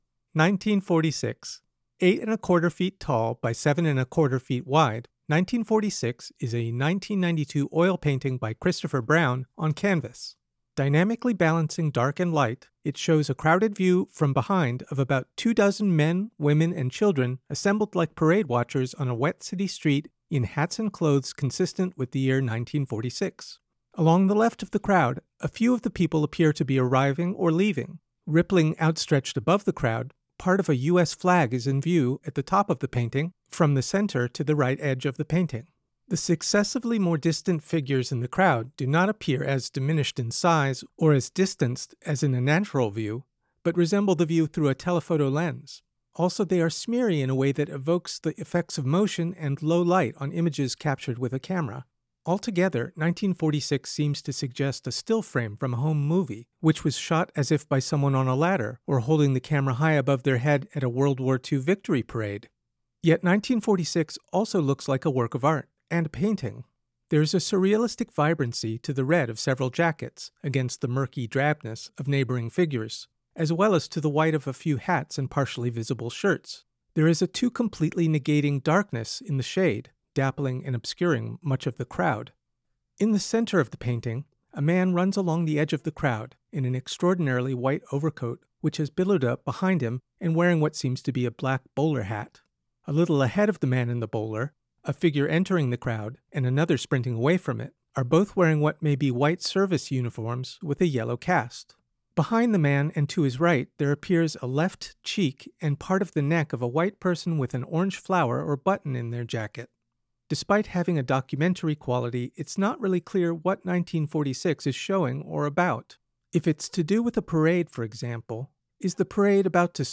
Audio Description (02:31)